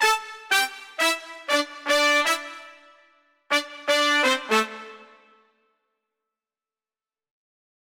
31 Brass PT1.wav